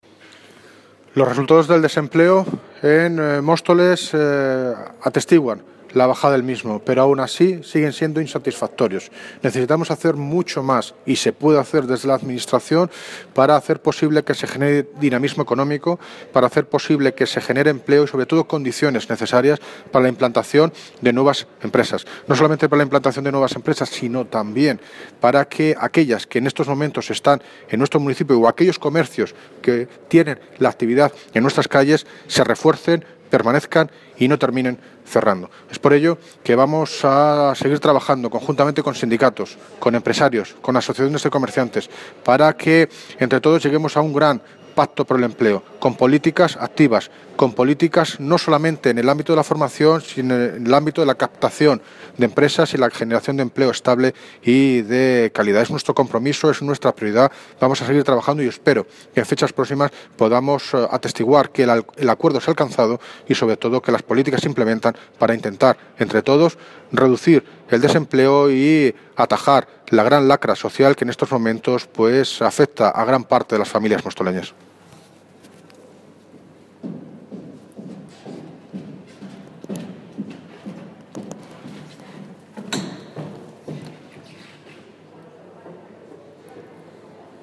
Audio - David Lucas (Alcalde de Móstoles) Sobre Desempleo en Móstoles